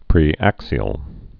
(prē-ăksē-əl)